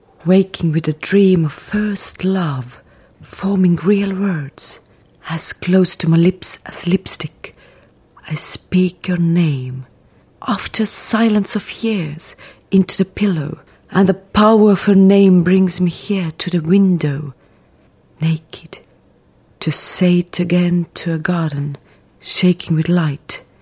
Estonian, Female, 20s-40s